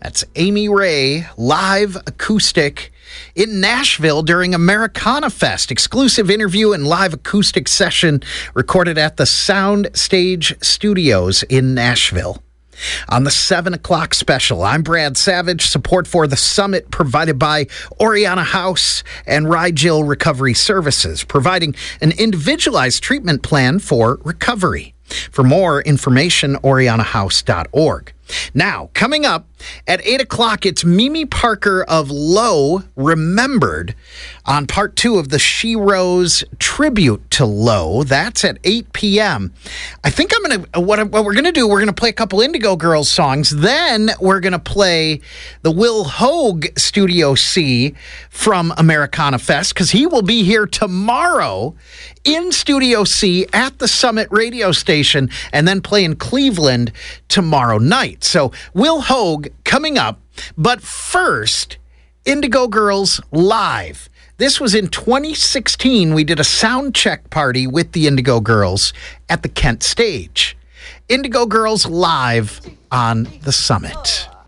(captured from webcast)
06. announcer (1:14)